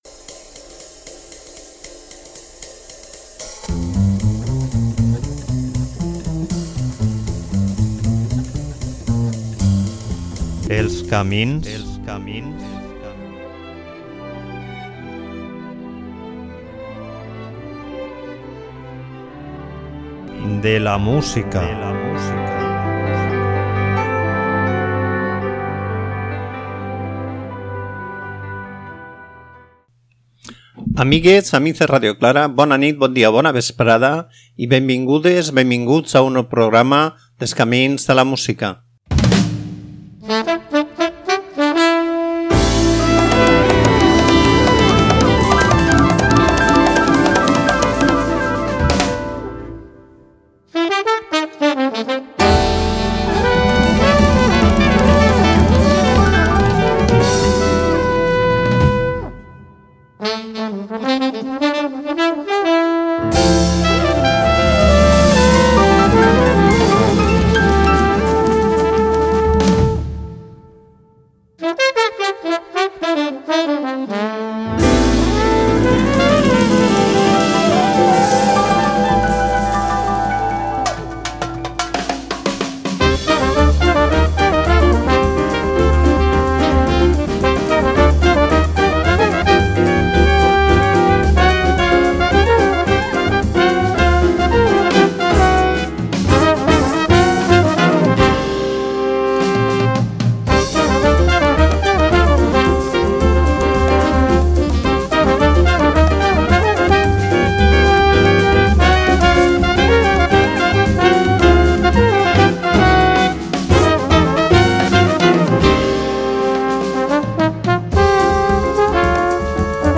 trombó
amb una veu suau i avellutada